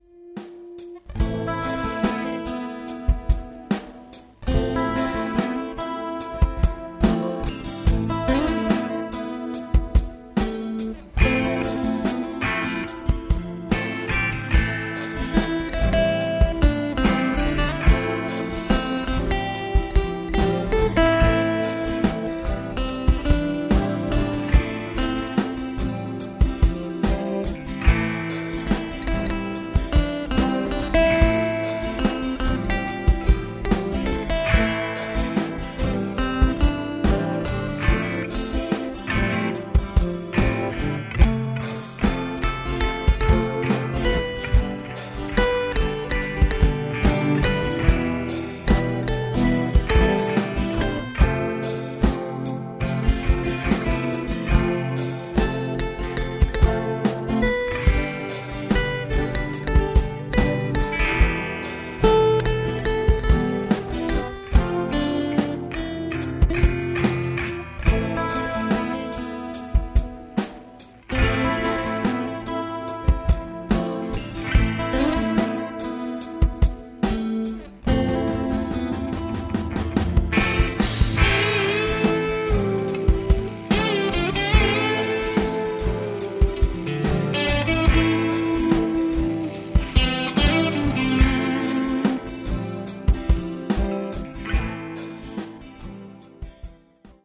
Home recording demo